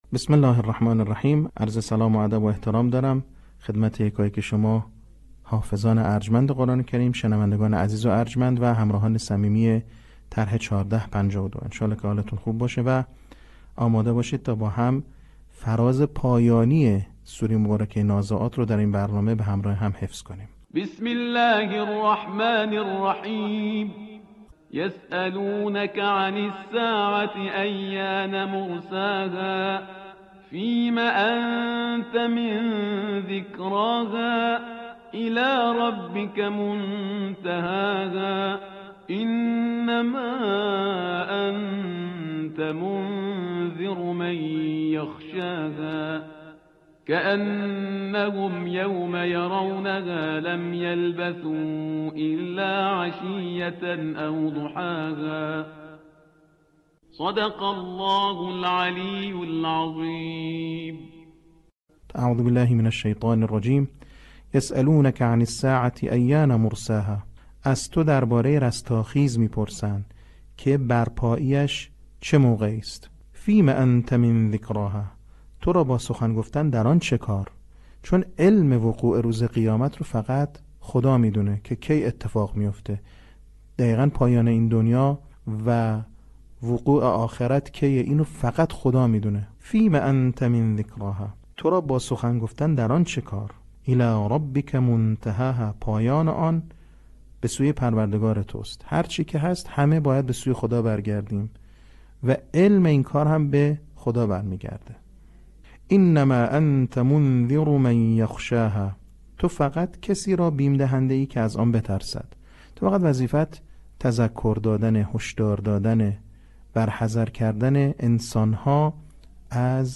صوت | بخش هفتم آموزش حفظ سوره نازعات